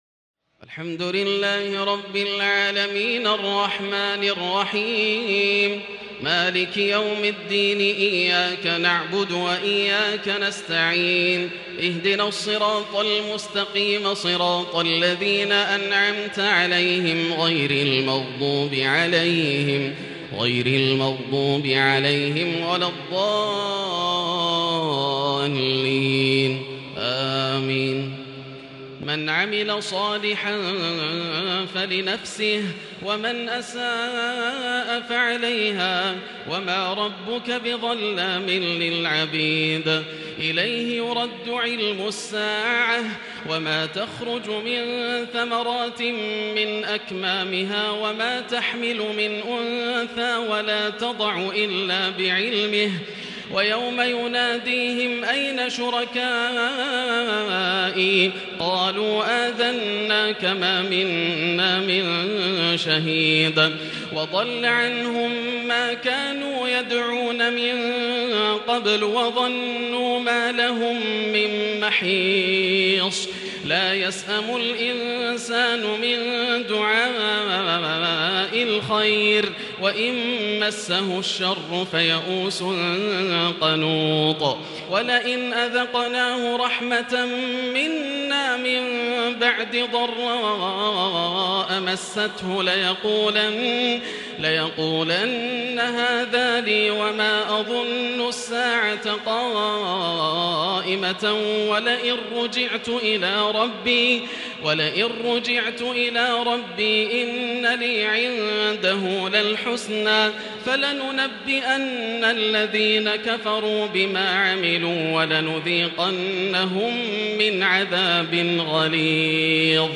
تراويح ليلة 24 رمضان 1440هـ من سور فصلت (46-54) و الشورى و الزخرف (1-25) Taraweeh 24 st night Ramadan 1440H from Surah Fussilat and Ash-Shura and Az-Zukhruf > تراويح الحرم المكي عام 1440 🕋 > التراويح - تلاوات الحرمين